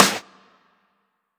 HitSounds
ka.wav